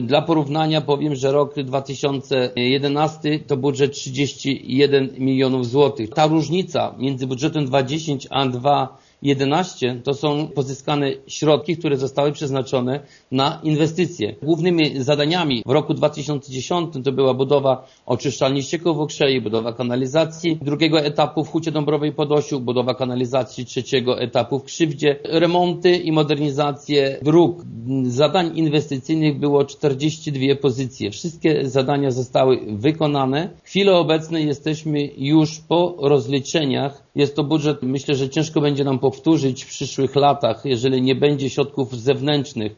Jerzy Kędra podkreśla, że budżet 2010 roku był budżetem rekordowym, ponieważ zamknął się historyczną kwotą 41,5 miliona złotych, z czego 17 milionów samorząd przeznaczył na inwestycje: